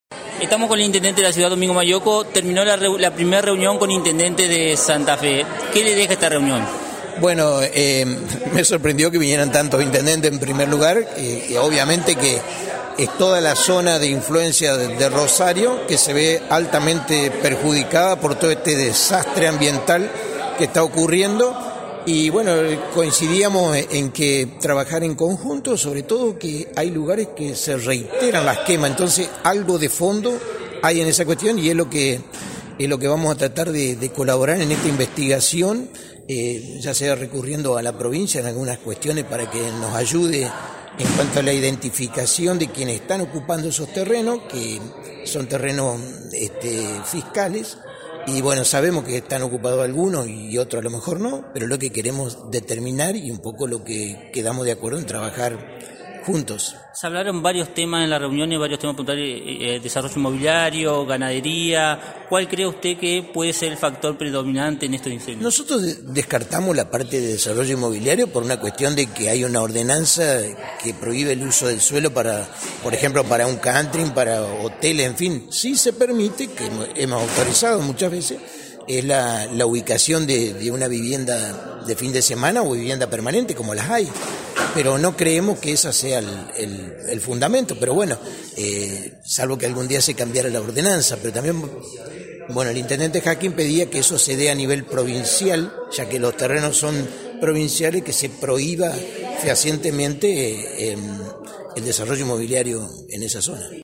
En diálogo con nuestros periodistas, los intendentes de rosario y Victoria coincidieron en la necesidad de un trabajo en conjunto, y en profundizar la investigación en determinados sectores donde los incendios se reiteran en forma inusual.
Intendente de Victoria – Domingo Maiocco